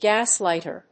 アクセントgás lìghter